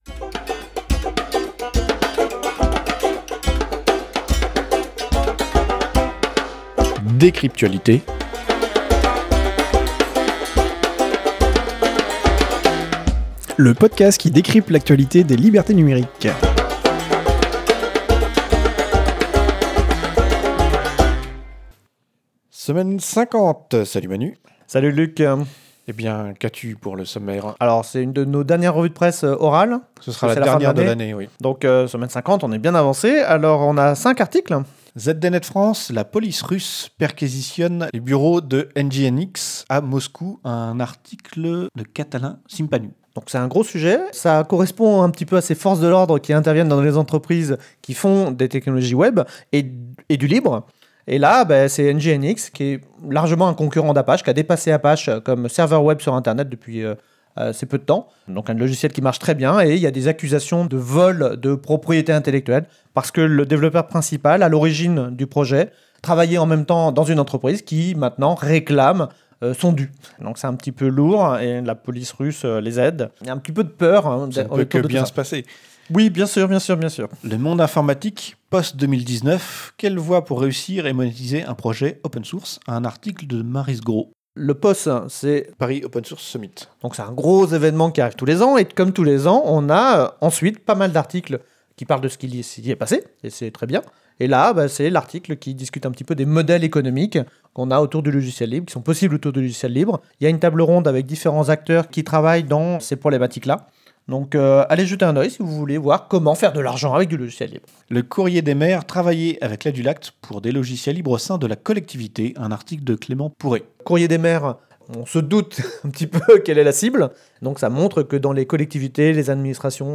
Studio d'enregistrement